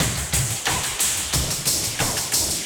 Index of /musicradar/rhythmic-inspiration-samples/90bpm